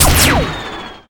pshoot1.ogg